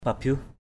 /pa-biuh/ (t.) vùng dưới, hạ lưu. dom palei gah pabiuh _d’ pl] gH b`H những làng ở vùng hạ lưu.
pabiuh.mp3